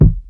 Kicks
What a World Kick.wav